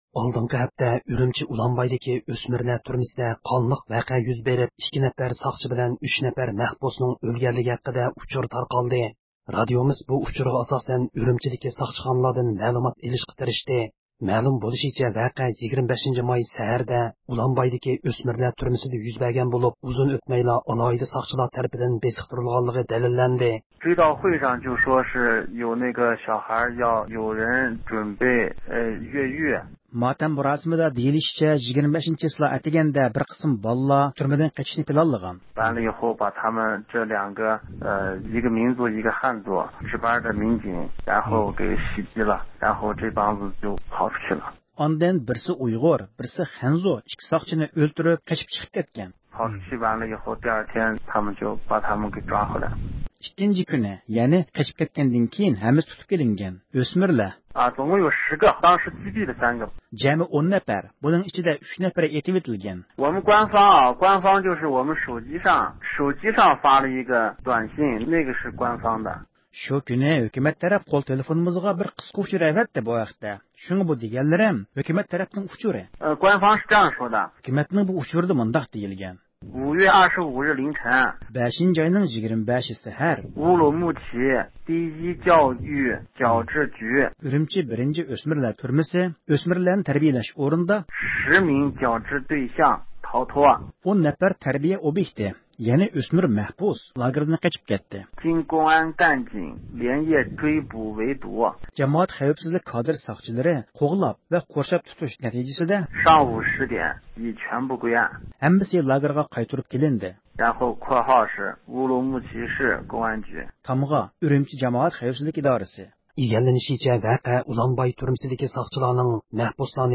erkin-asiya-radiosi.jpgئەركىن ئاسىيا رادىئوسى ئۇيغۇر بۆلۈمى ھەپتىلىك خەۋەرلىرى